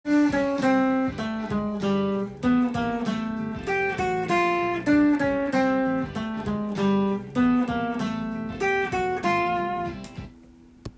コード進行は、C/G/Am/F
コードトーン練習TAB譜
譜面通り弾いてみました